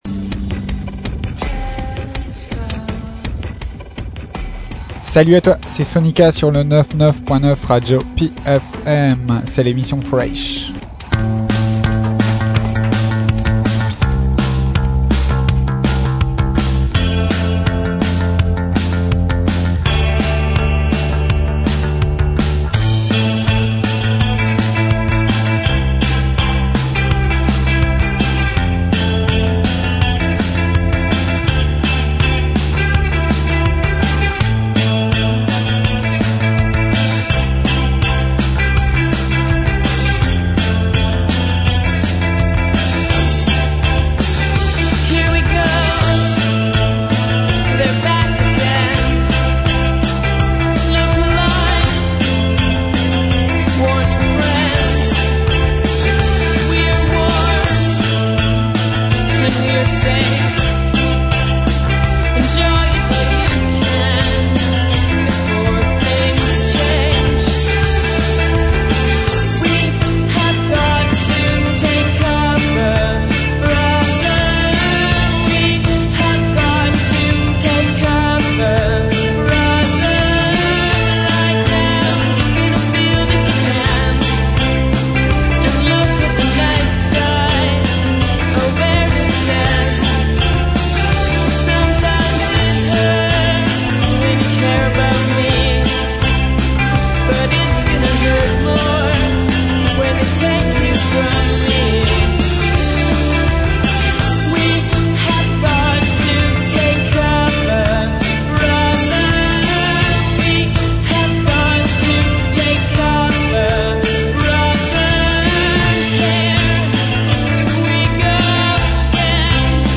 SoniKa en live sur RADIO PFM 99.9 le dimanche à 17h !!